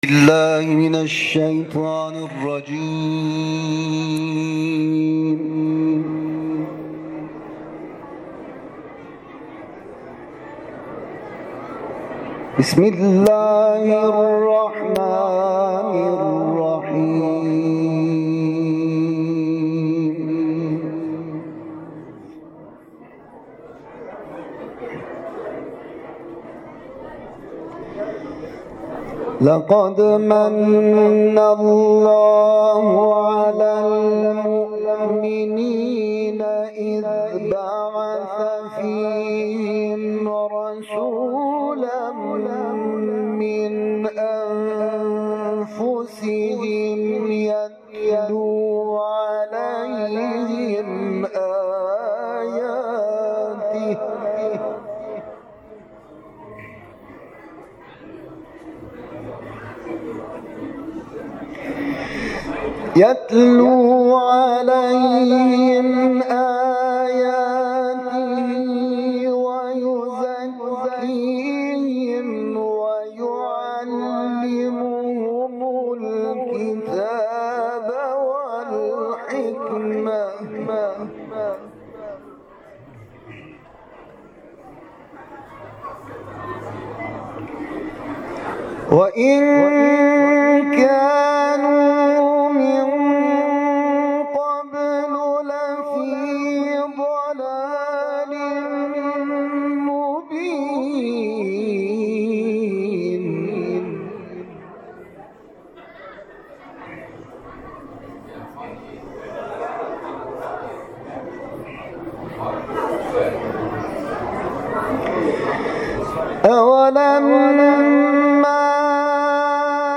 تلاوت
قاری و حافظ قرآن کریم به تلاوت آیاتی از سوره مبارکه آل عمران پرداخته است.